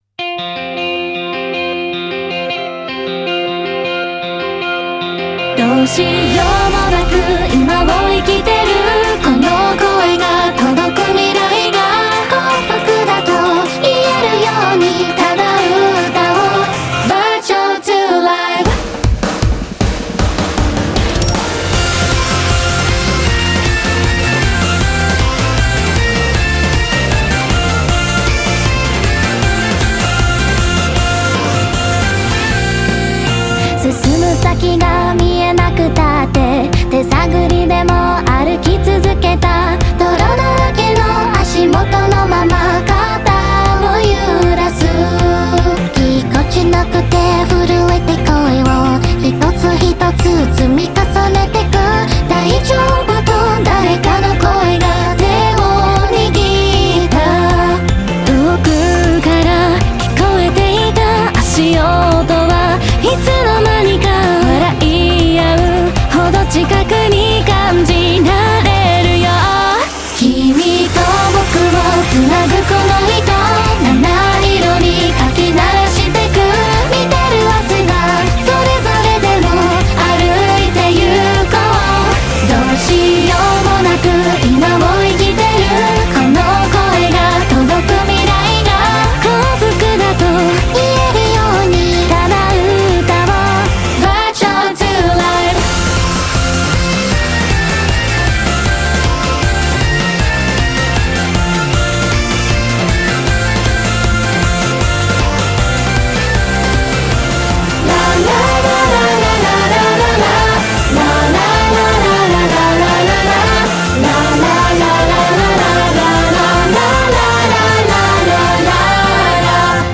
BPM156
Audio QualityMusic Cut